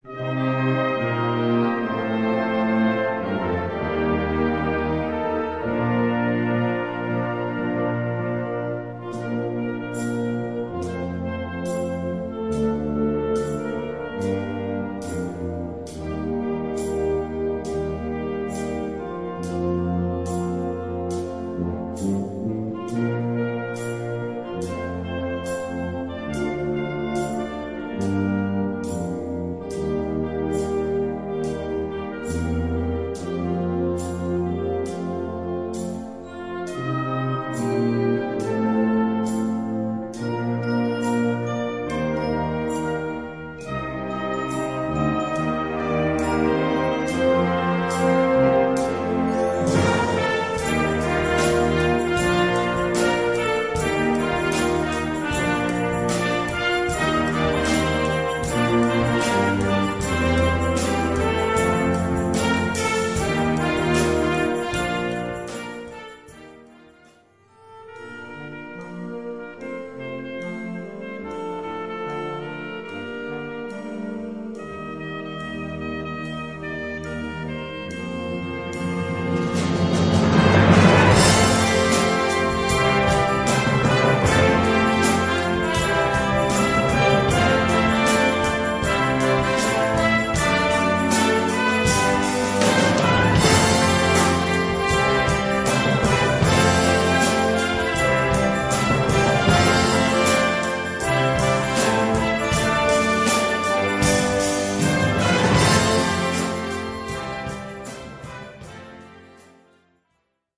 Moderne Blasmusik
Blasorchester